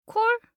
알림음 8_콜1-여자.mp3